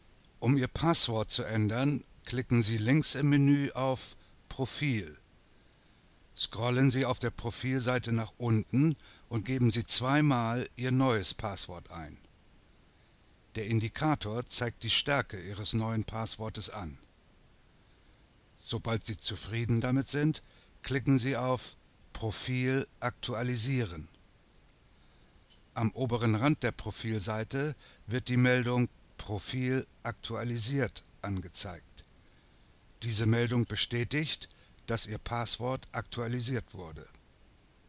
Native German speaker; professional, soothing, distinct middle age voice
Sprechprobe: eLearning (Muttersprache):